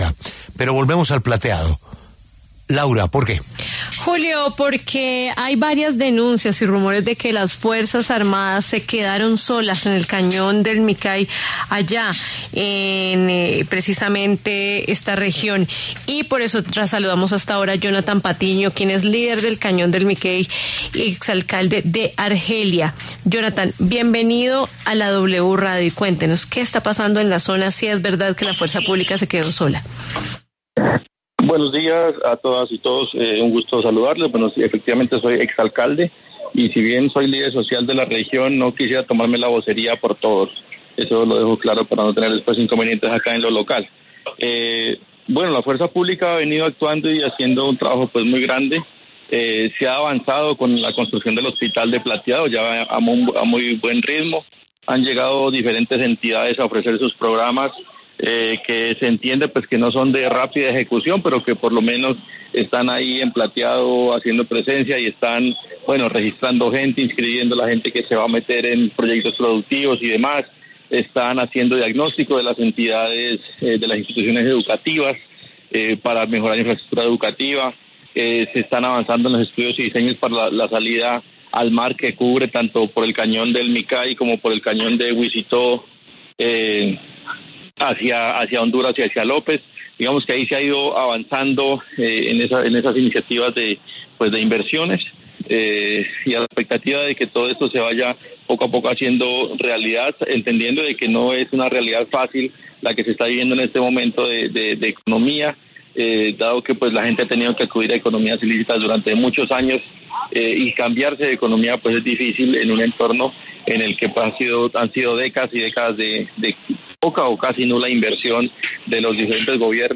El exmandatario Jhonathan Patiño pasó por los micrófonos de La W e hizo un balance de la inversión social tras la ejecución de la ‘Operación Perseo’ en el corregimiento de El Plateado.